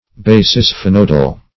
Search Result for " basisphenoidal" : The Collaborative International Dictionary of English v.0.48: Basisphenoid \Ba`si*sphe"noid\, Basisphenoidal \Ba`si*sphe*noid"al\, a. [Basi- + sphenoid.]